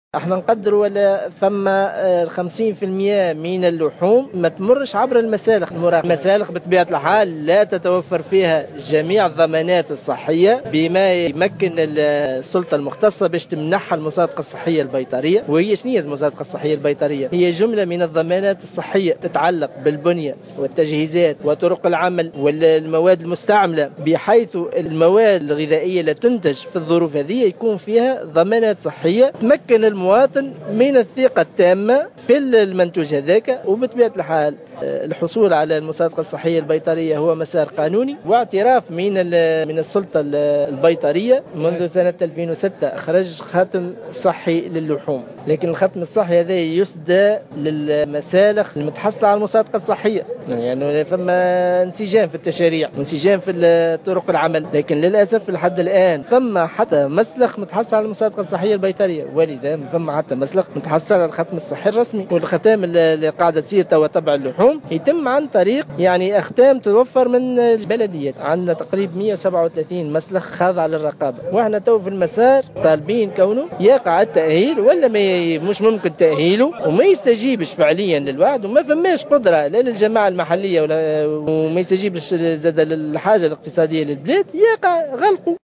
خلال مؤتمر صحفي
في تصريح للجوهرة "أف أم"